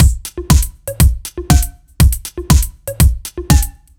Index of /musicradar/french-house-chillout-samples/120bpm/Beats
FHC_BeatD_120-02.wav